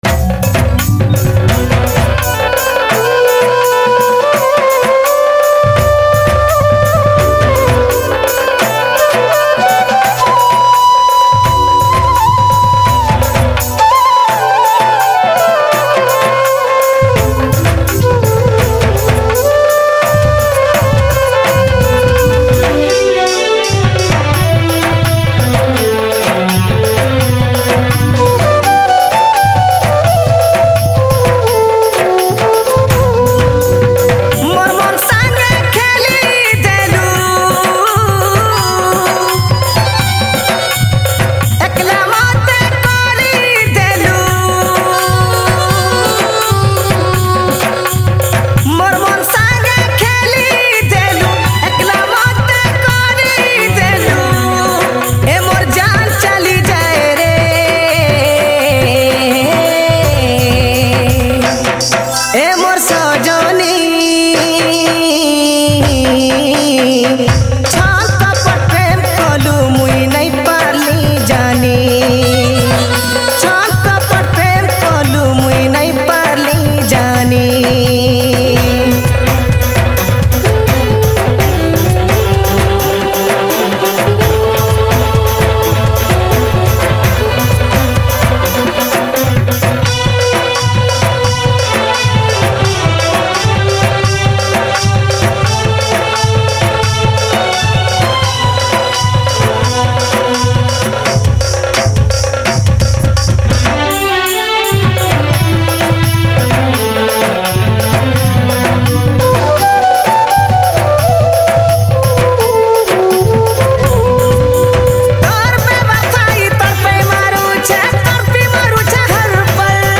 Sambalpuri Songs